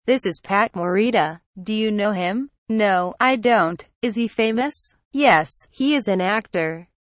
Conversation Dialog #2: